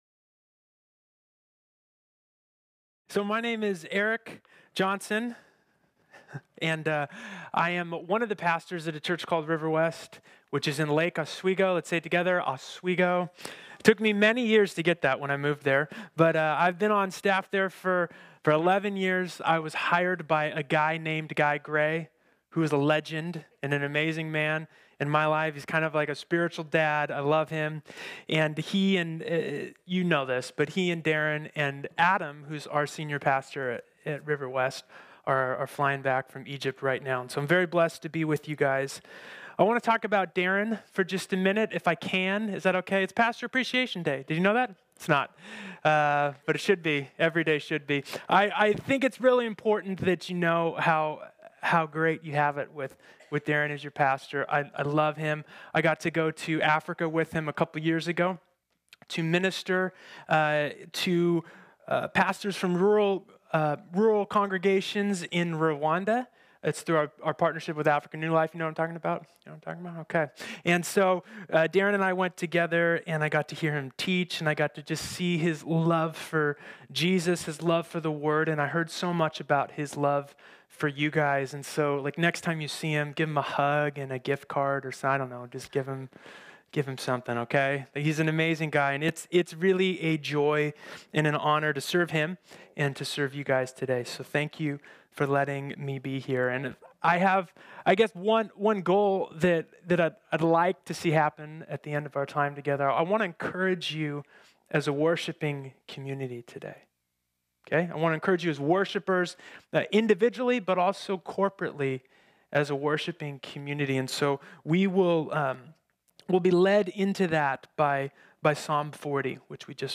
This sermon was originally preached on Sunday, January 24, 2021.